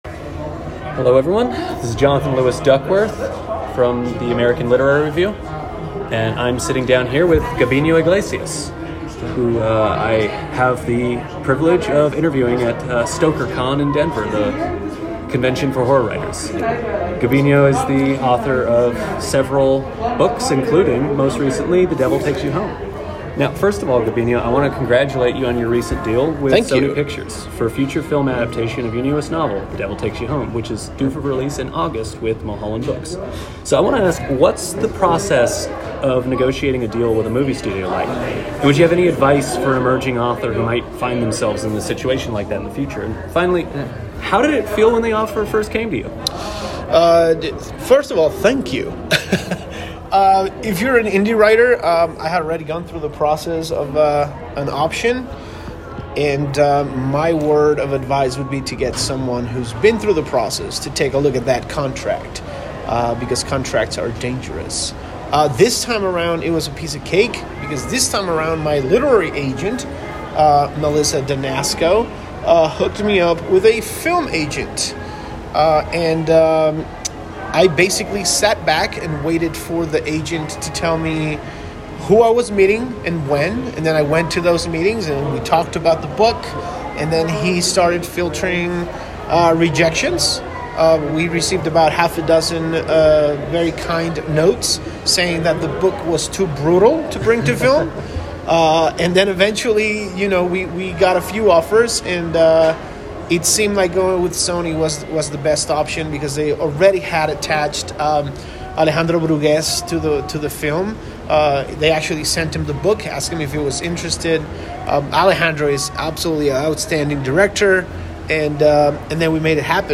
You can listen to the full, unedited audio file, below.